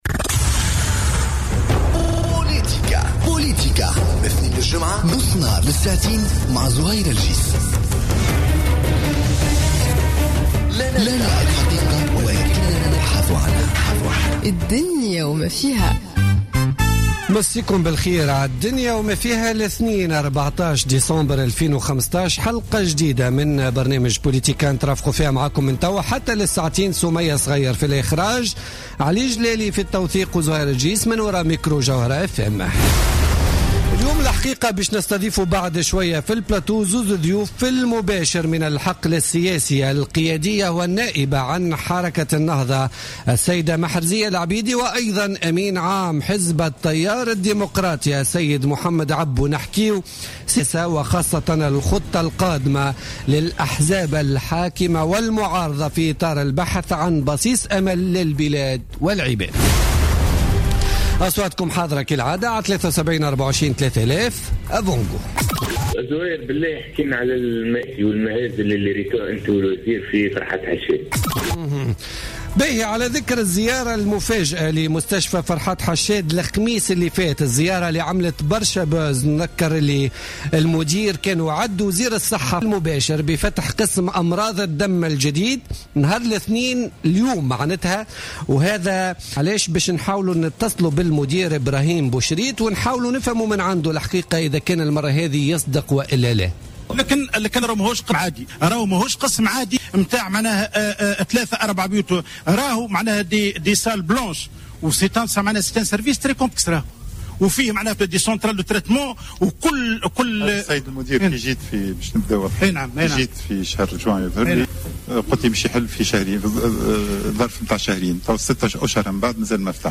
Interview avec Mohamed Abbou et Meherzia Laâbidi